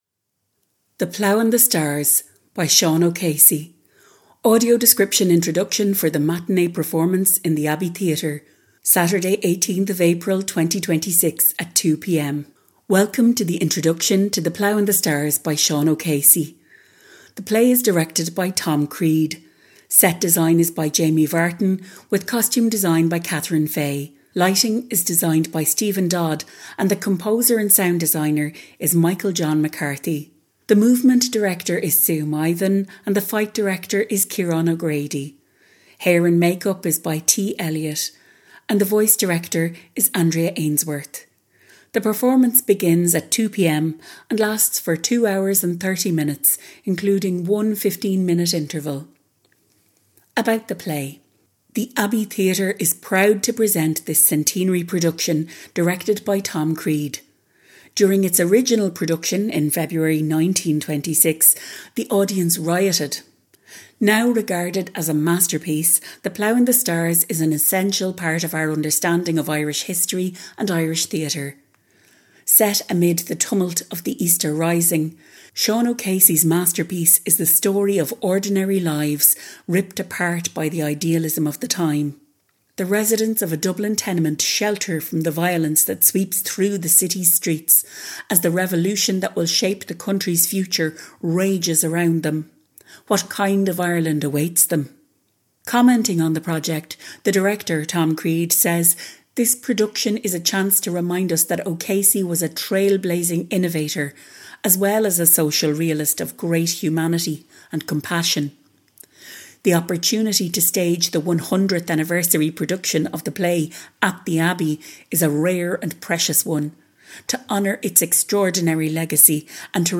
Audio Described Content
The-Plough-and-the-Stars_AD-Introduction.mp3